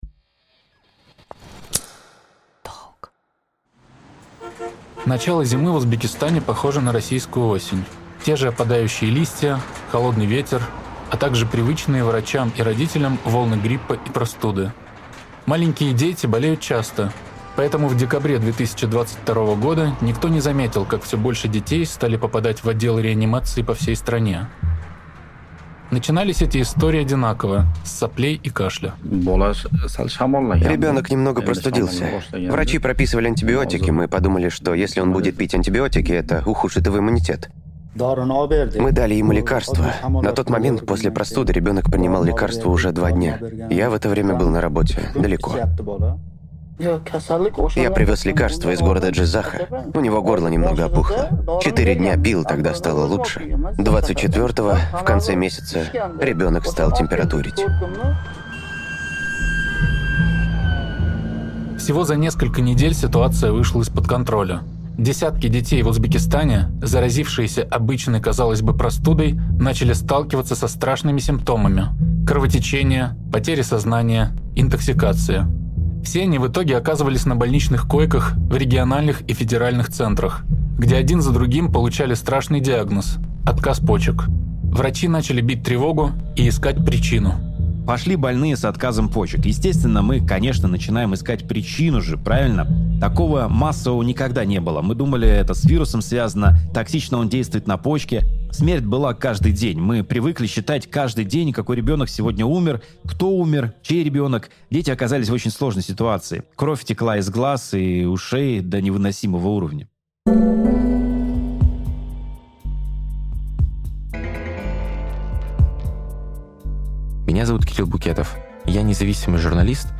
Рассказчик — писатель Алексей Поляринов.